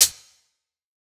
Closed Hats
TS HiHat_8.wav